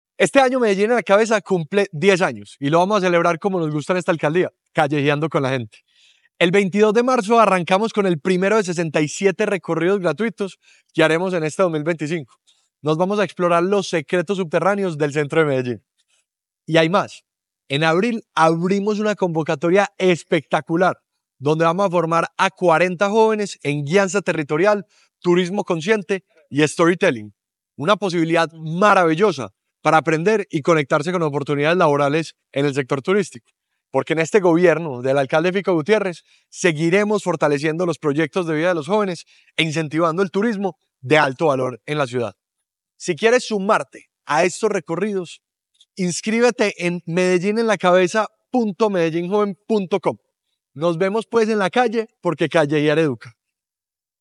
Audio Palabras de Ricardo Jaramillo, secretario de la Juventud La Administración Distrital emprende de nuevo los recorridos de Medellín en la Cabeza, un programa que fomenta el conocimiento y el amor de los jóvenes por su ciudad.